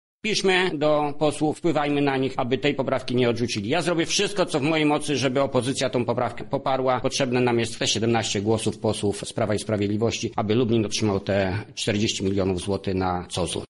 – mówi senator Jacek Bury.